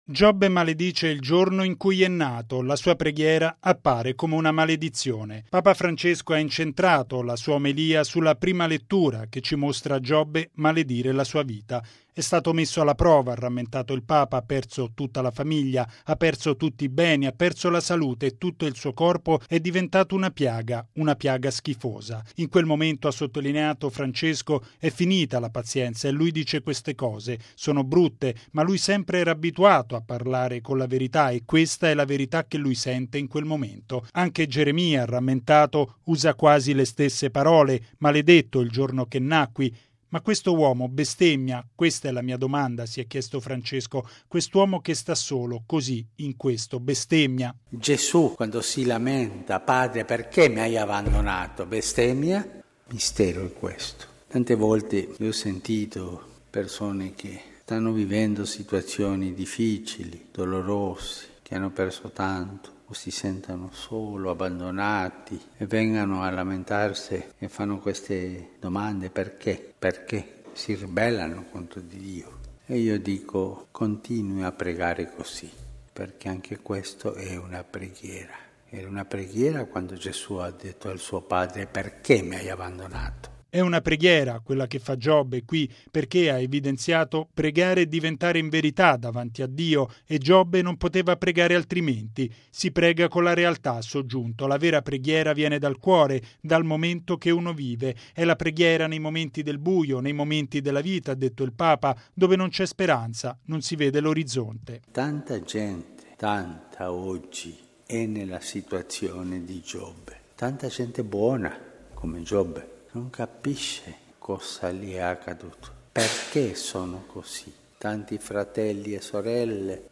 E’ quanto sottolineato da Papa Francesco nella Messa mattutina a Casa Santa Marta.